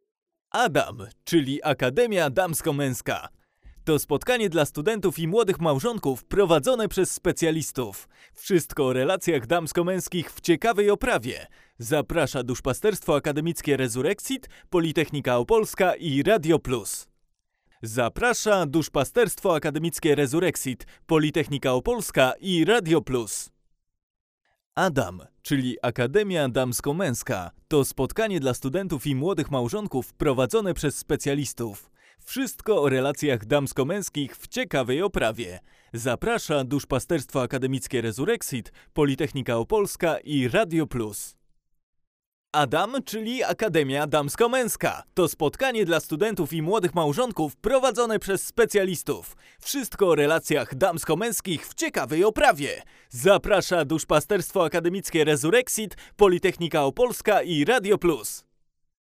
Neumann TLM-103 + UAudio 710